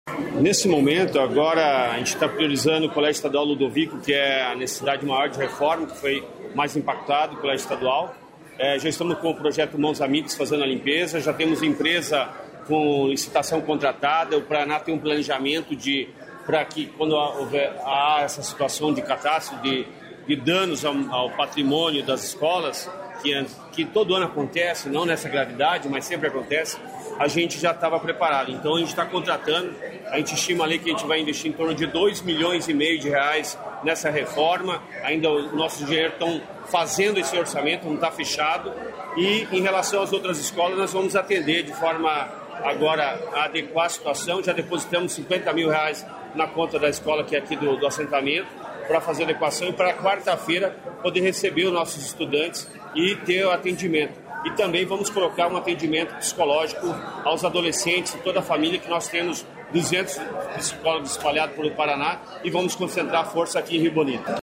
Sonora do secretário da Educação, Roni Miranda, sobre a reconstrução das escolas em Rio Bonito do Iguaçu